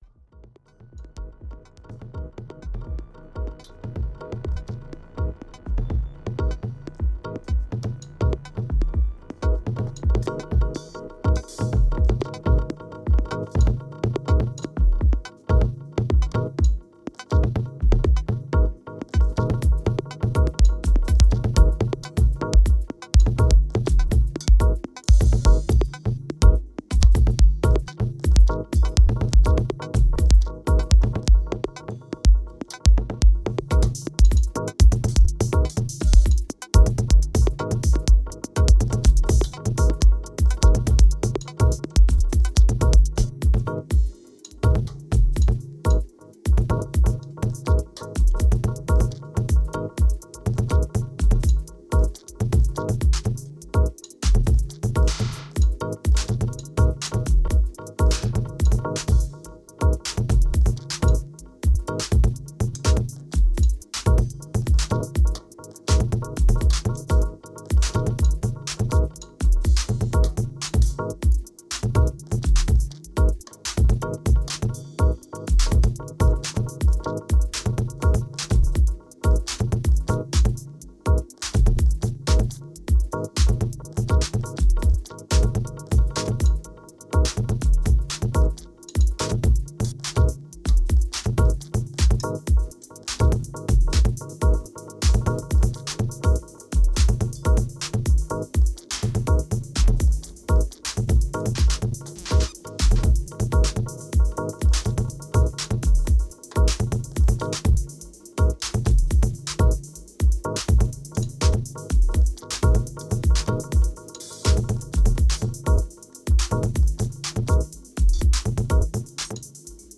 experimental improvisation live electronics